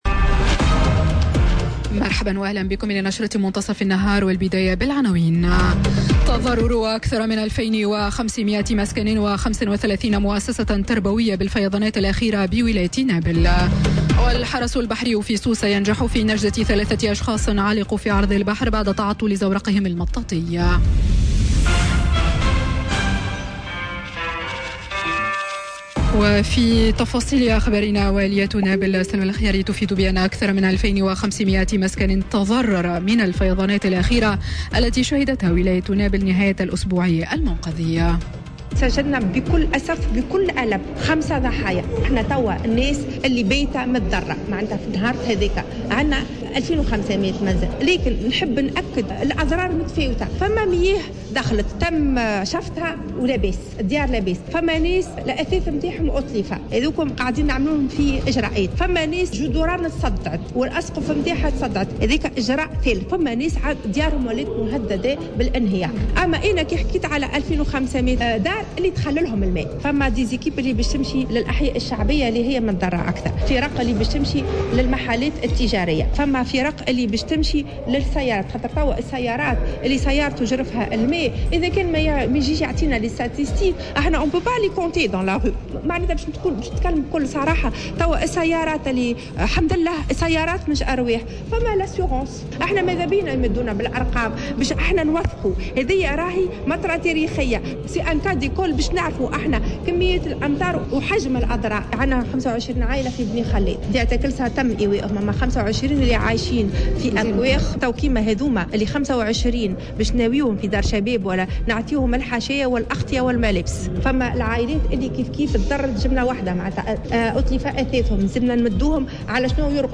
نشرة أخبار منتصف النهار ليوم الإثنين 24 سبتمبر 2018